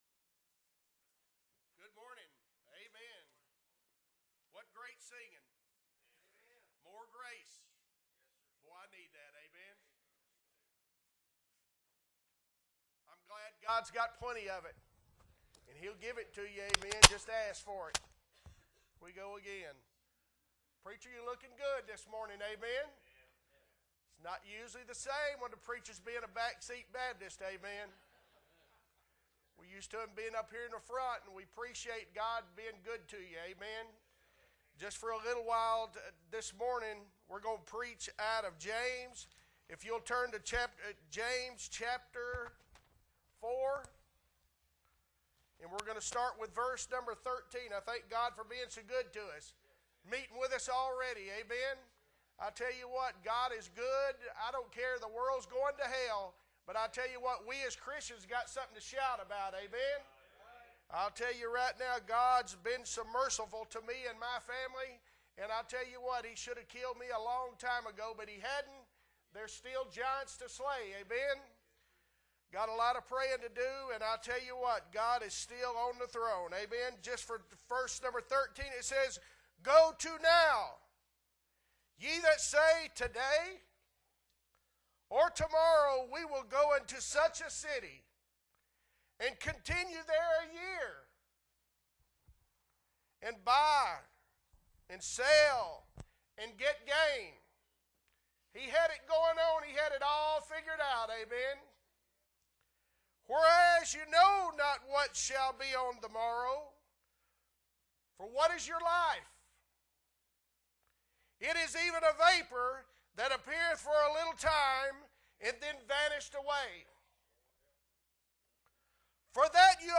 Morning Service James 4_13 If the Lord's Will